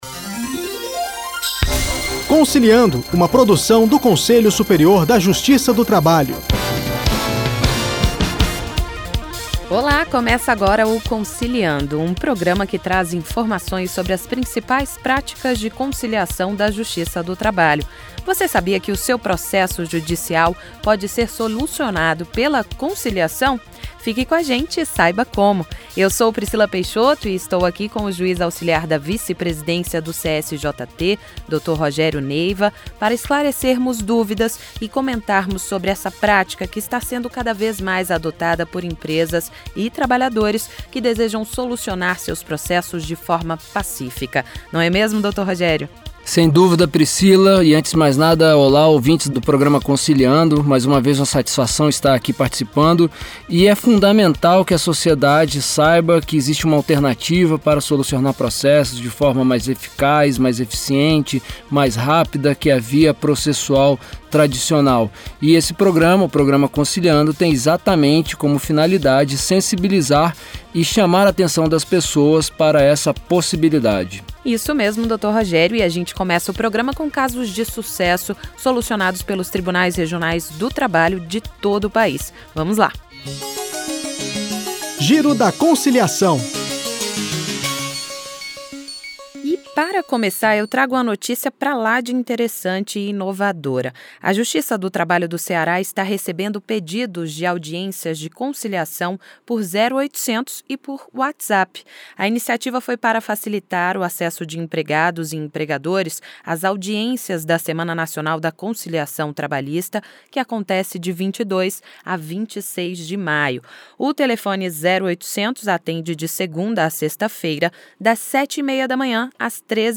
Na “Dica-do-dia” o juiz do trabalho Rogério Neiva explica o que ela é e se tem relação com a conciliação
No quadro de entrevista, o juiz coordenador do Centro de Conciliação do Tribunal Regional do Trabalho da 4ª Região no Rio Grande do Sul (TRT-RS), Luiz Tati, fala sobre o funcionamento do Núcleo de Conciliação no TRT da 4ª Região e como estão os preparativos para a Semana Nacional da Conciliação Trabalhista.